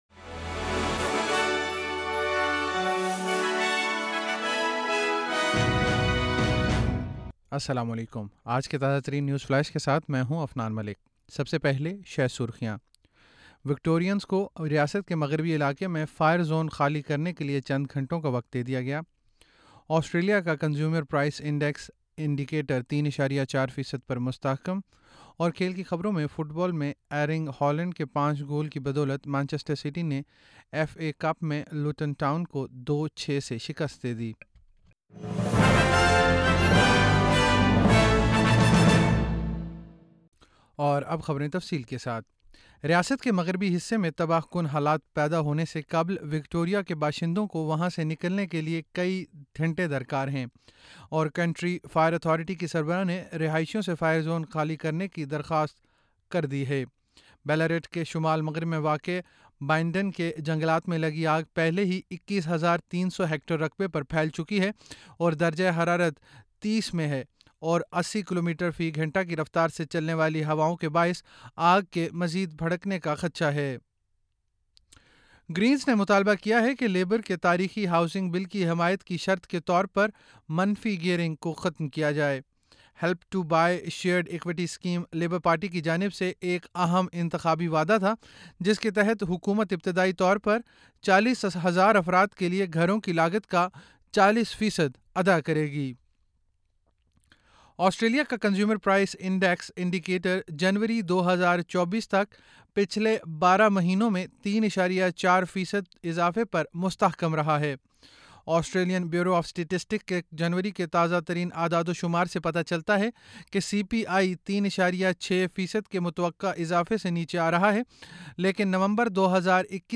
نیوز فلیش 28 فروری 2024: وکٹورینز کو ریاست کے مغربی علاقے میں فائر زون خالی کرنے کے لئے چند گھنٹوں کا وقت